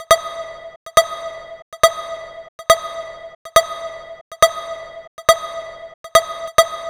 Lead 139-BPM E.wav